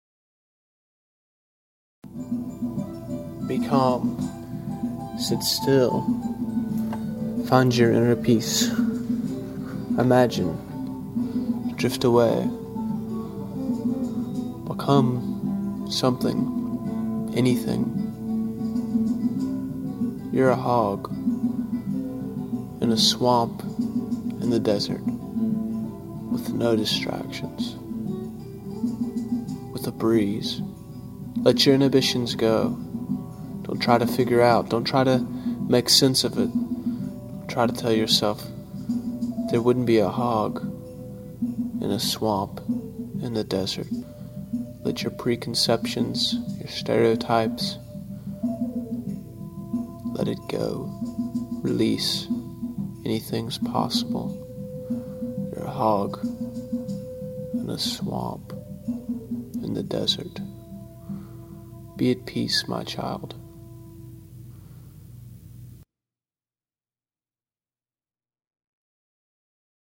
This Guided Meditation Mantra is For You 231
Please enjoy my free guided meditation mantra.